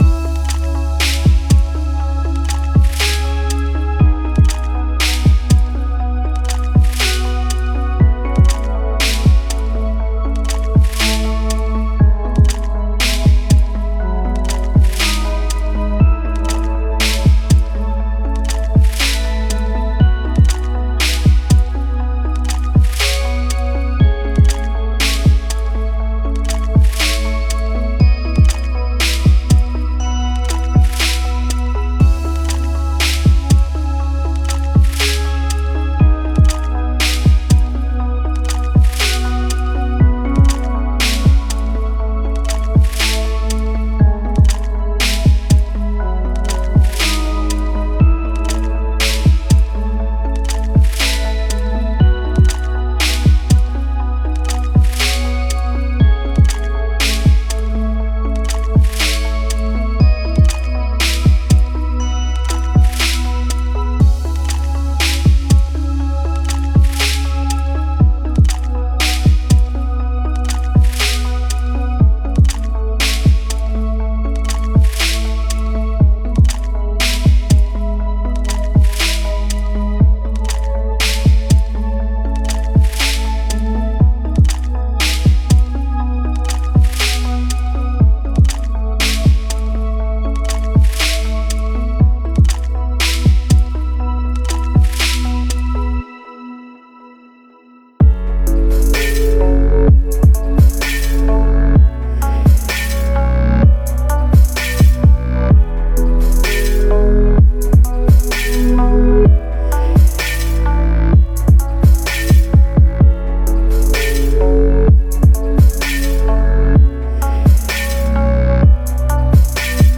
Chill Out / Lounge